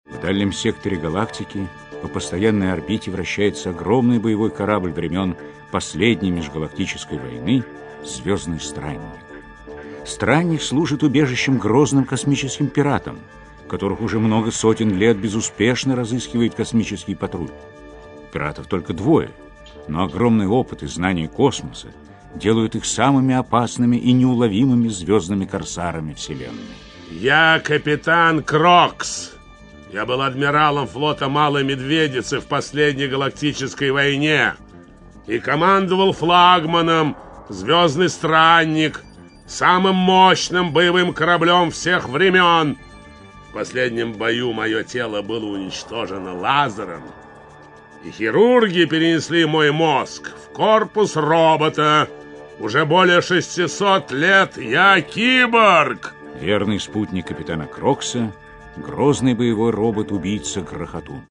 Аудиокнига Сердце пирата (спектакль) | Библиотека аудиокниг
Aудиокнига Сердце пирата (спектакль) Автор Дмитрий Емец Читает аудиокнигу Евгений Весник.